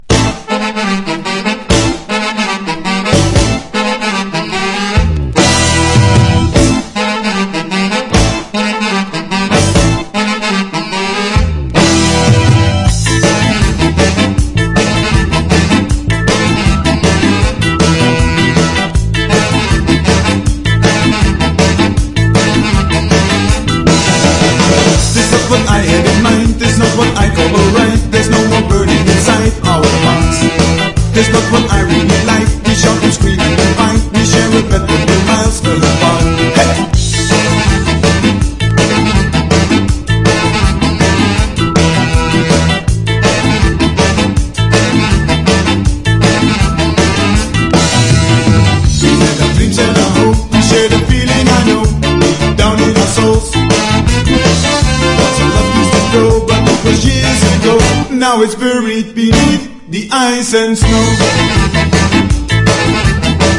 WORLD / REGGAE / SKA/ROCKSTEADY / NEO SKA